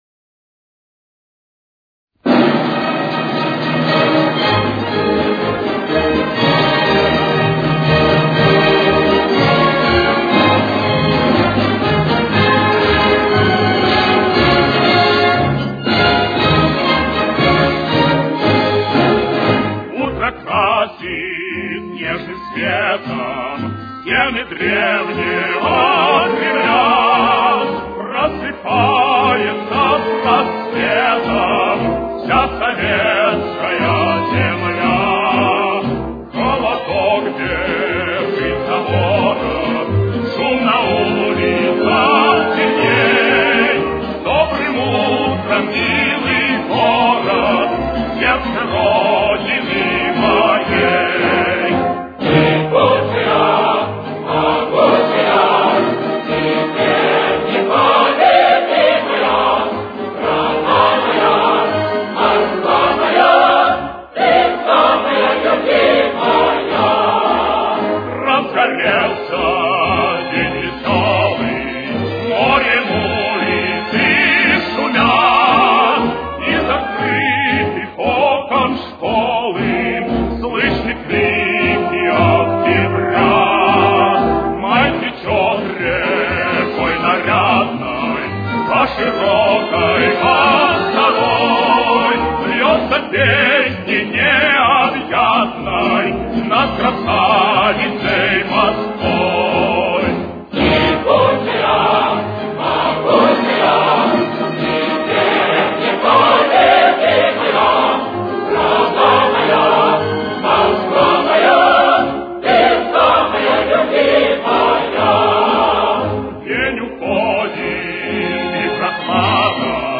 Фа минор. Темп: 120.